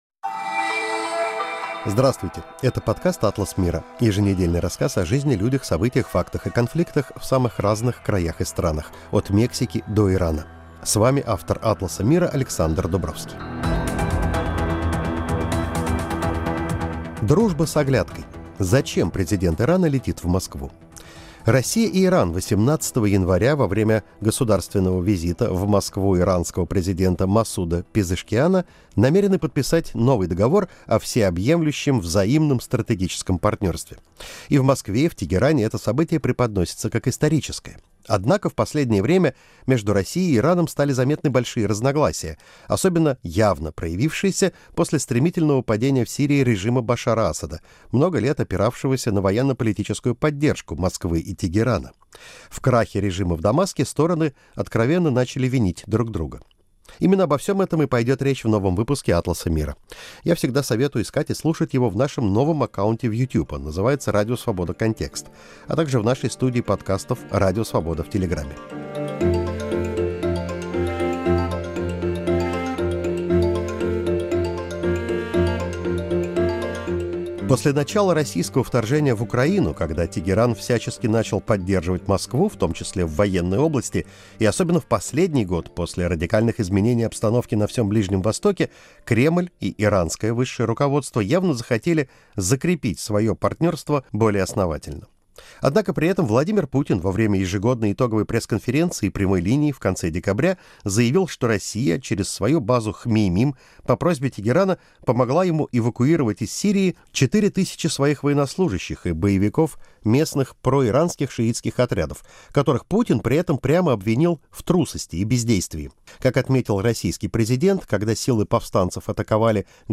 Каждую неделю журналисты-международники беседуют о жизни, людях, событиях, фактах и конфликтах за пределами России и США.